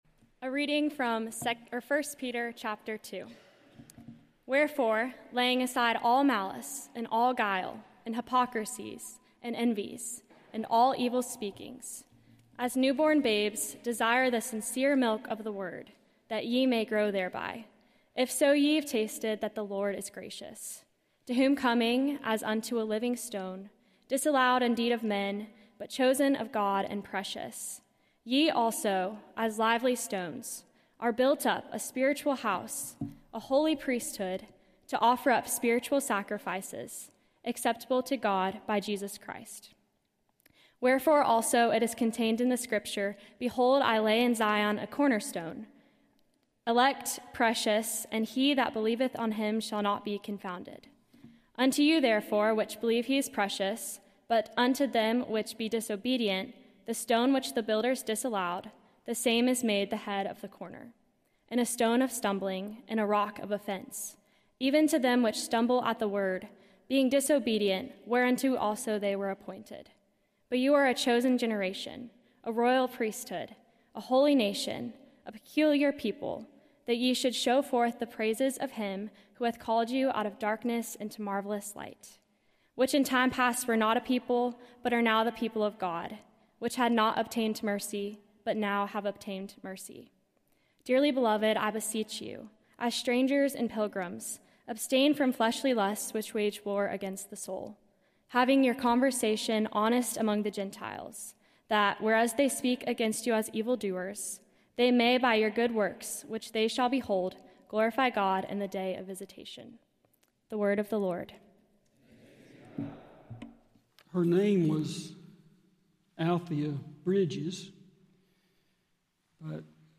Beeson Divinity School Chapel Services